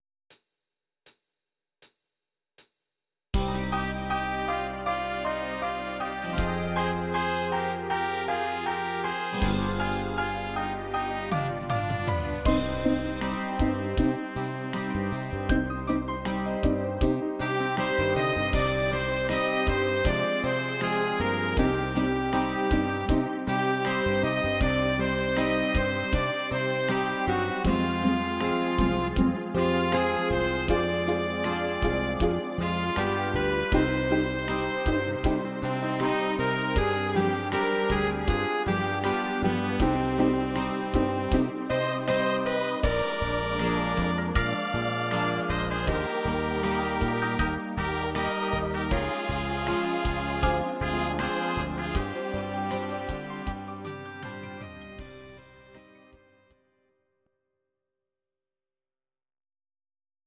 These are MP3 versions of our MIDI file catalogue.
Please note: no vocals and no karaoke included.
instr. Klarinette